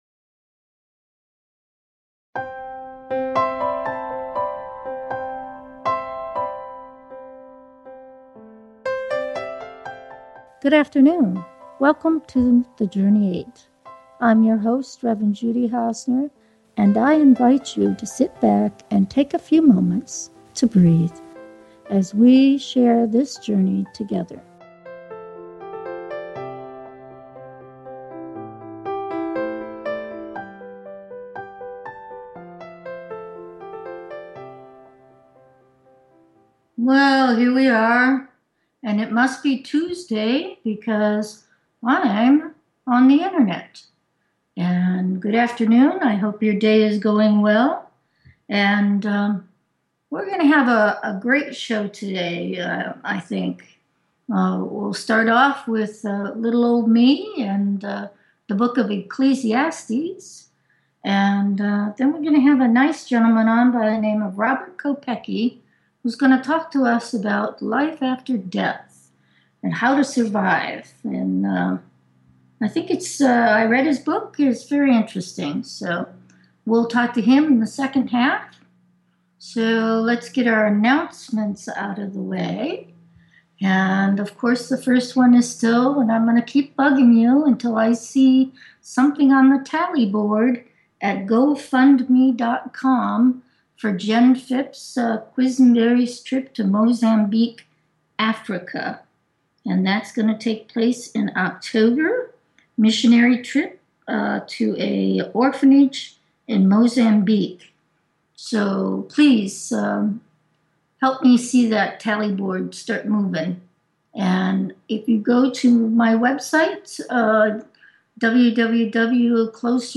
Talk Show Episode
The Journey 8 is a Christian based talk show that crosses the barriers of fear and hatred to find understanding and tolerance for all of God’s children.